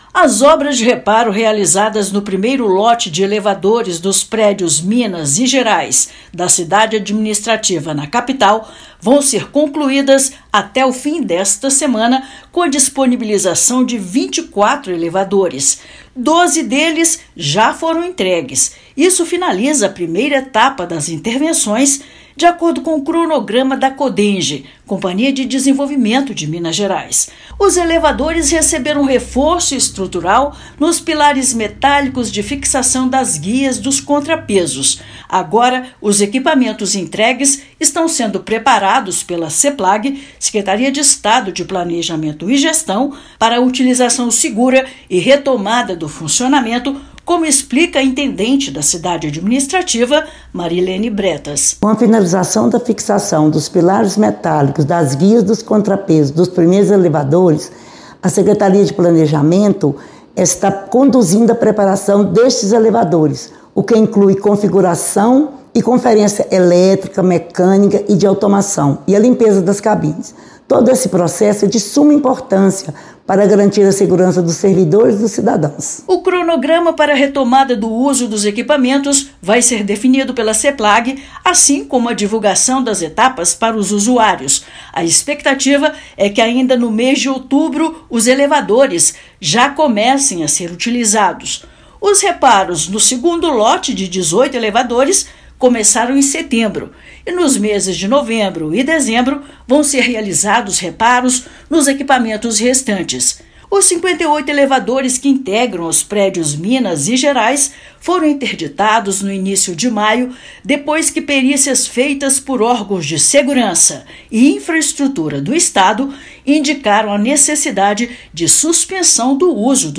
Conforme cronograma definido, Seplag-MG inicia, agora, a fase de preparo, limpeza e testes nos equipamentos entregues, para garantir o conforto e segurança dos usuários. Ouça matéria de rádio.